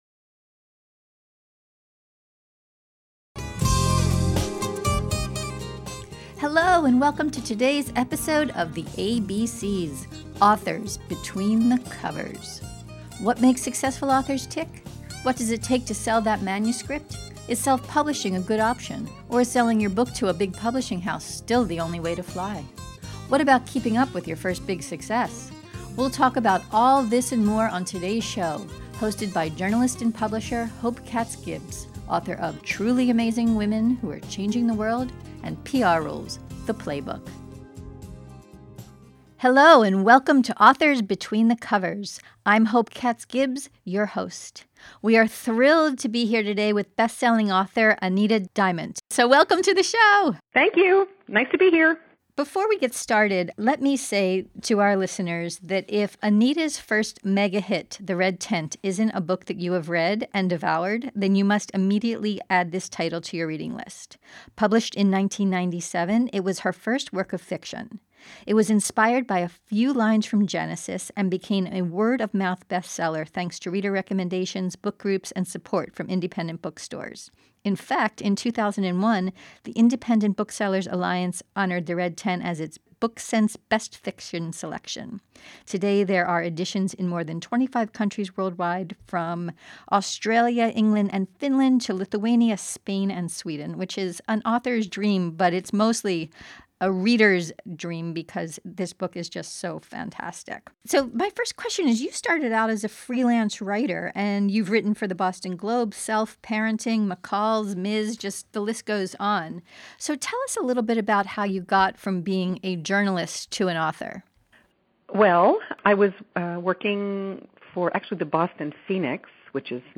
Don’t miss our conversation with Diamant, who told us: How she moved from journalism into novel writing How she picks her subjects About her six